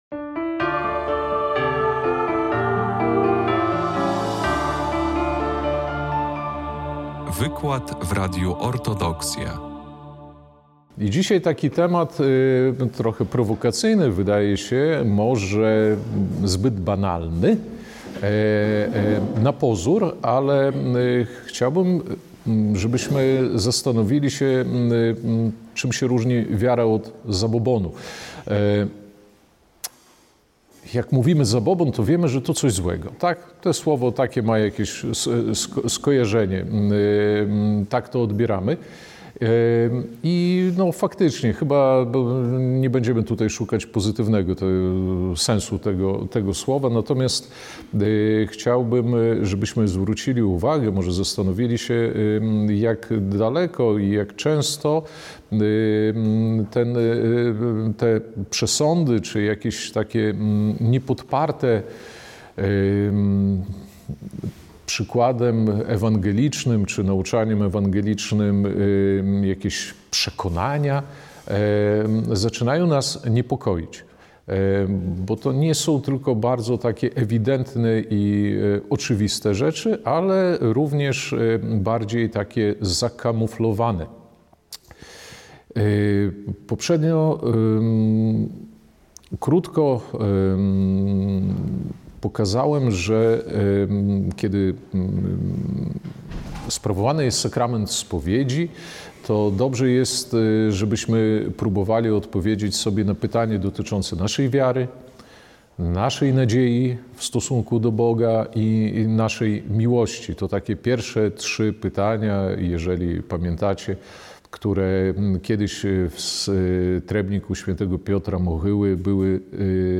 16 marca 2025 r. w parafii Zmartwychwstania Pańskiego w Białymstoku odbył się drugi wykład w ramach Wielkopostnej Wszechnicy.